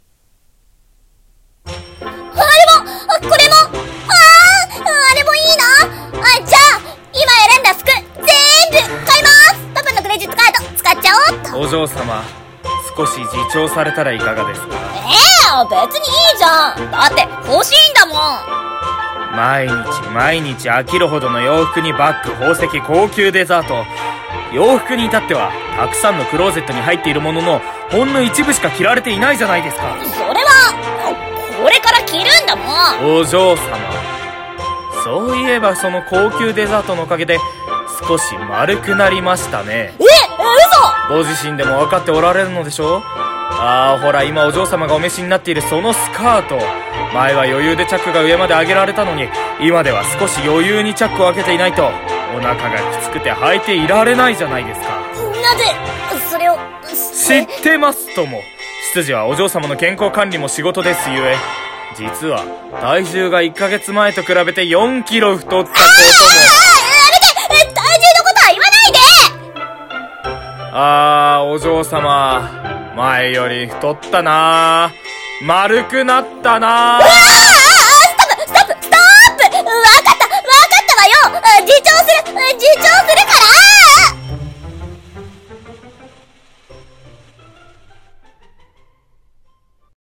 【声劇】 自重してください